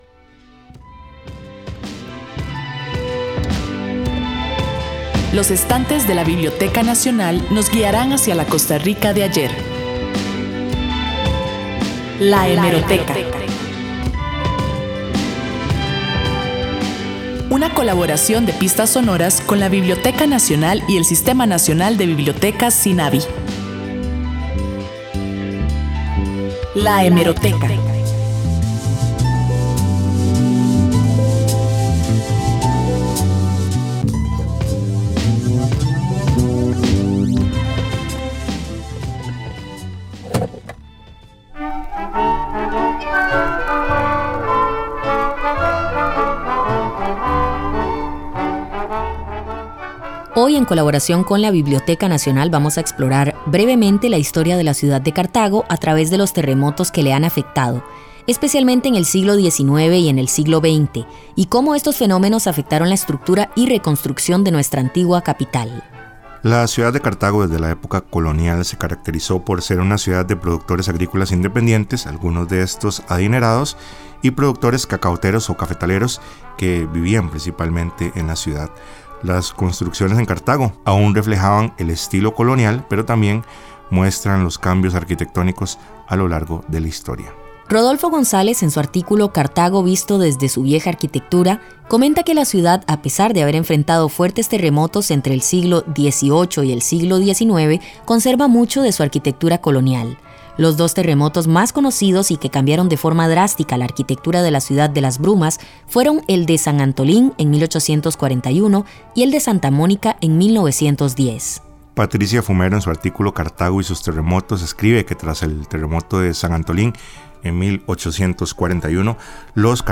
Sección de la Biblioteca Nacional en el Programa Pistas Sonoras de Radio Universidad, transmitido el 22 de octubre 2023. Este espacio es una coproducción de las Radios de la Universidad de Costa Rica y la Biblioteca Nacional con el propósito de difundir la cultura costarricense.